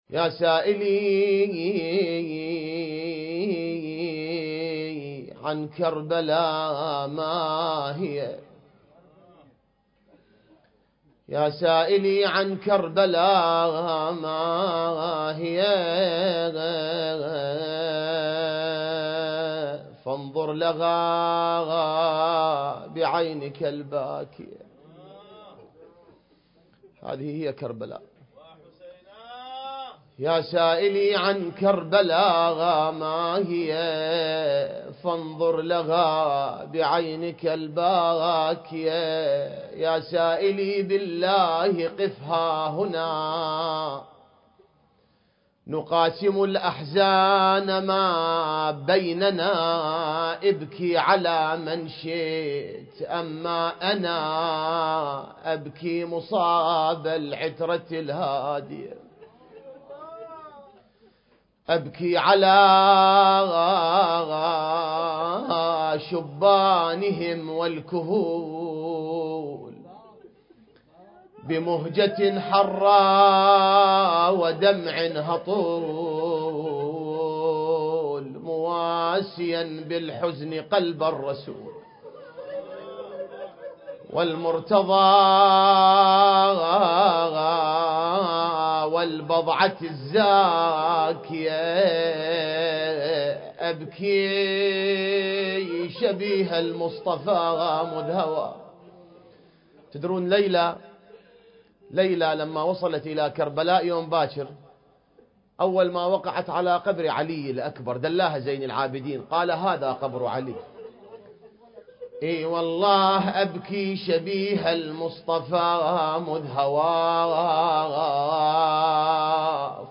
المحاضرات
الحجم: 13.2 MB | التاريخ: 19 صفر 1433 للهجرة | المكان: مجلس عائلة المدحوب/ البحرين